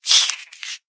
sounds / mob / silverfish / kill.ogg